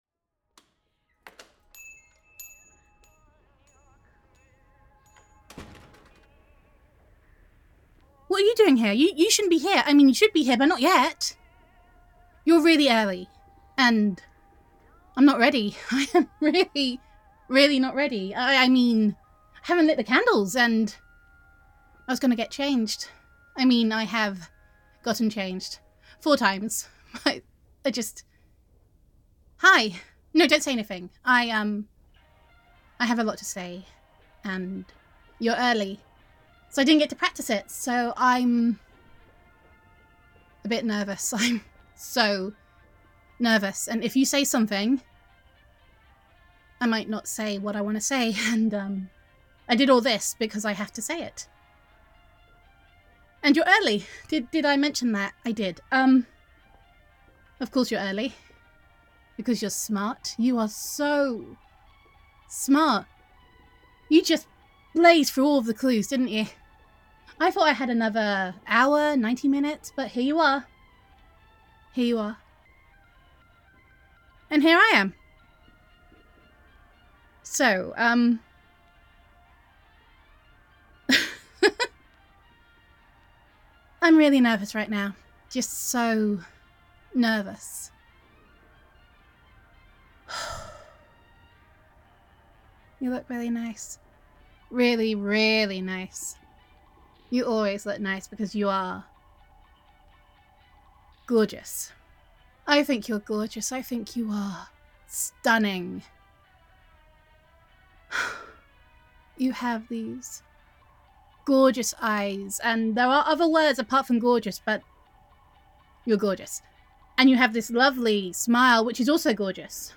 [F4A] Believe In Your Elf [Christmas Scavenger Hunt][Friends to Lovers][Best Friend Roleplay][Flustered][Love Confession][The Best Laid Plans][Gender Neutral][You Can’t Have a Scavenger Hunt Without the First Clue, Which Will Makes This Love Confession a Little Awkward]